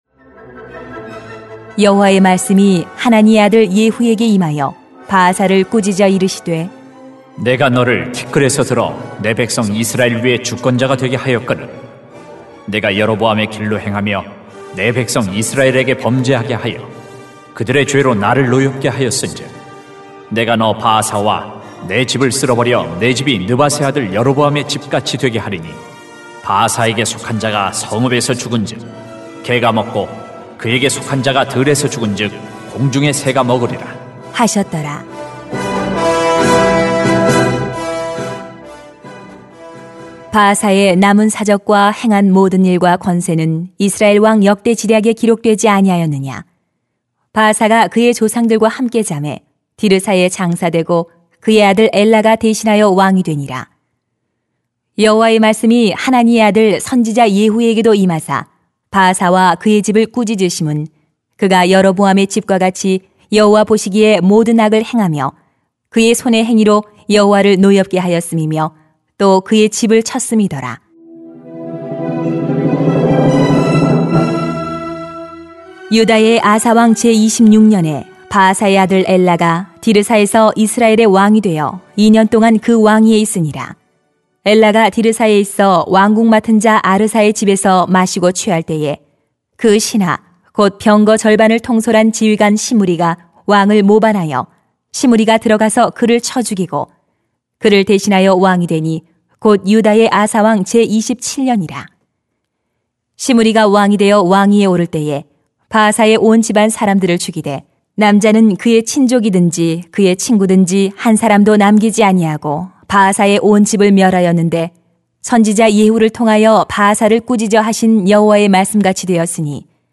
[왕상 15:33-16:14] 예수님만이 우리의 산 소망이십니다 > 새벽기도회 | 전주제자교회